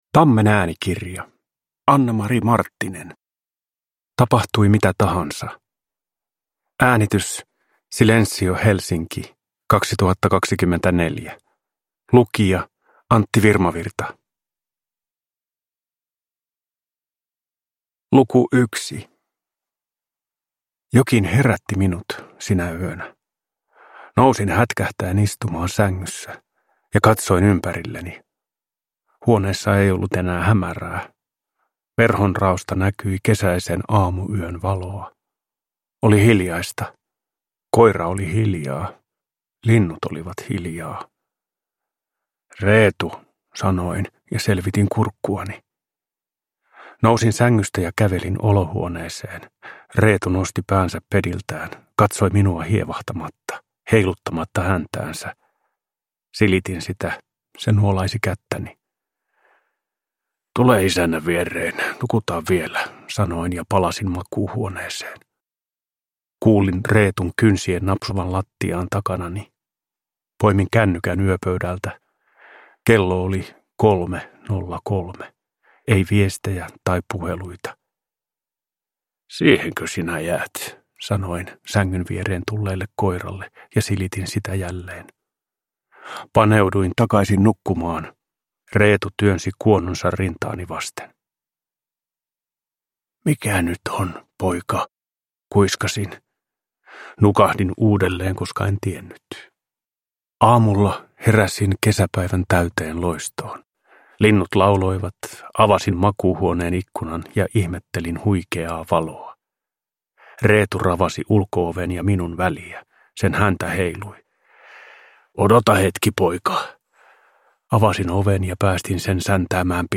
Tapahtui mitä tahansa – Ljudbok